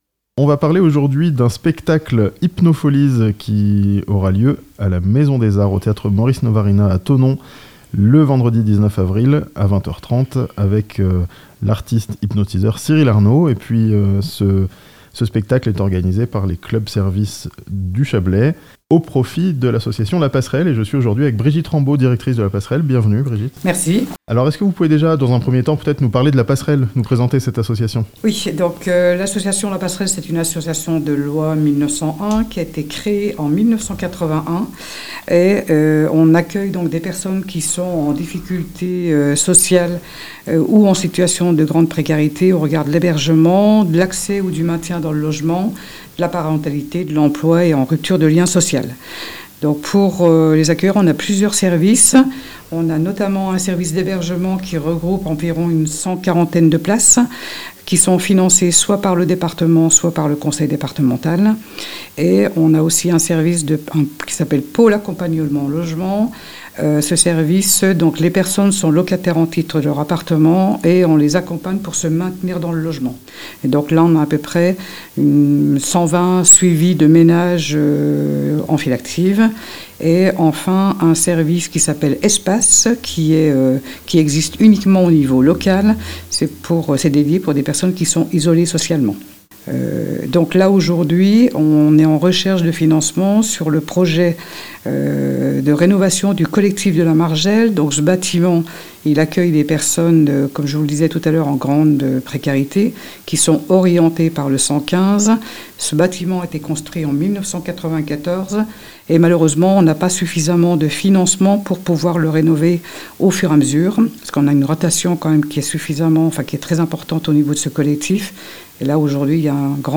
Un spectacle d'hypnose à Thonon, au profit de l'association La Passerelle (interviews)